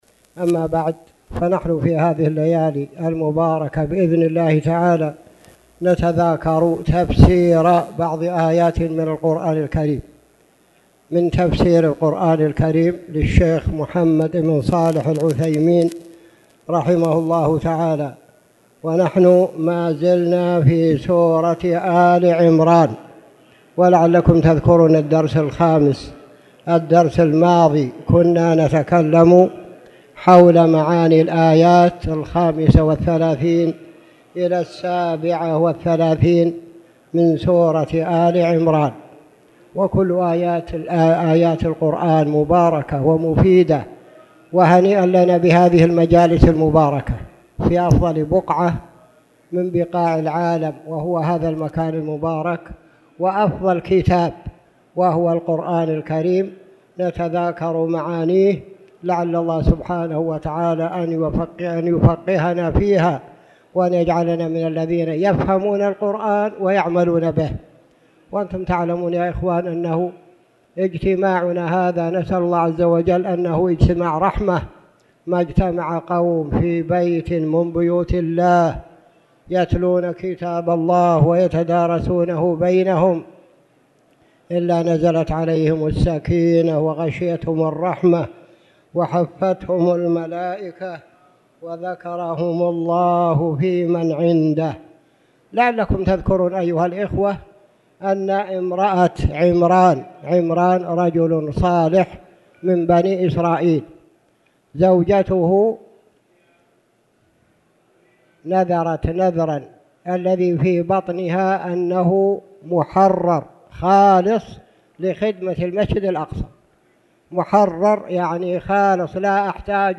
تاريخ النشر ١٤ جمادى الأولى ١٤٣٨ هـ المكان: المسجد الحرام الشيخ